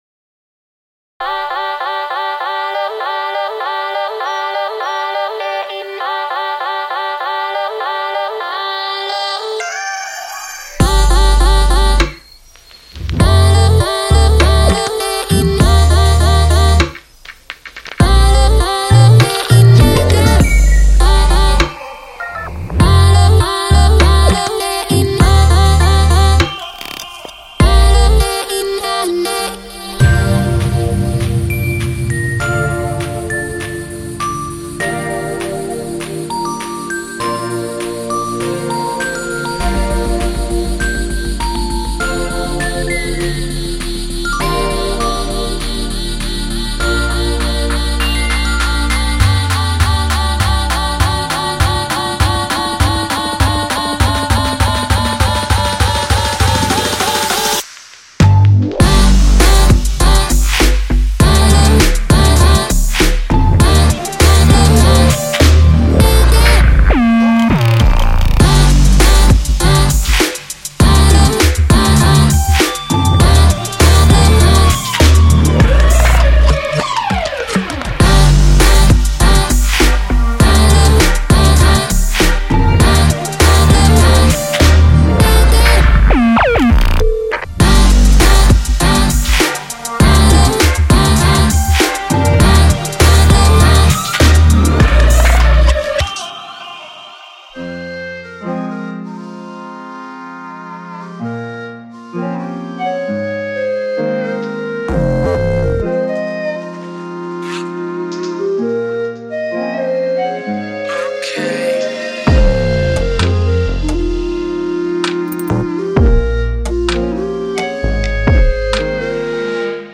• 003 x Mixed & Mastered FullMix / Preview Demos – (.WAVs)
• 008 x (Vocal Chops)